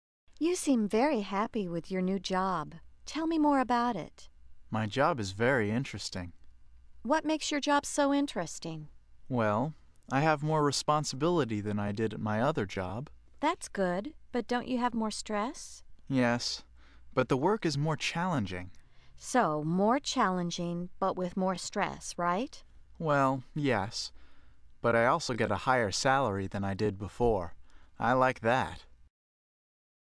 Listen to this conversation between two co-workers and check the words and expressions you know.